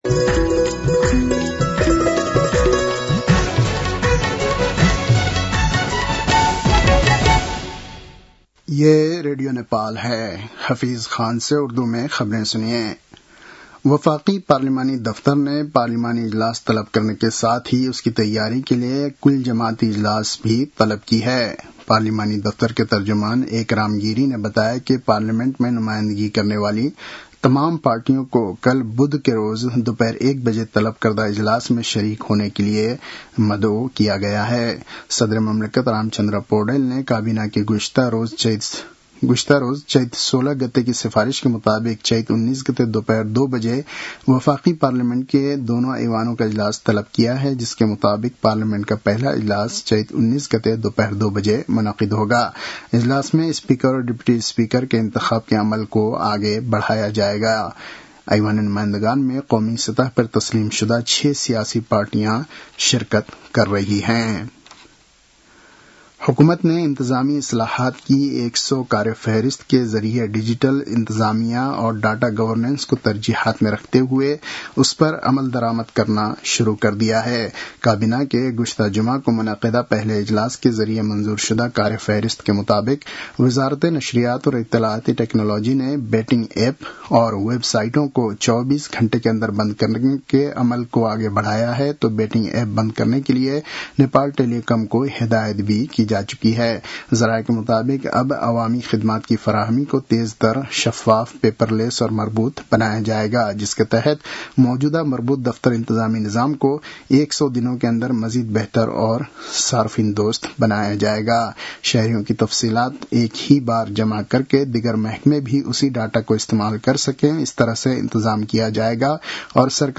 उर्दु भाषामा समाचार : १७ चैत , २०८२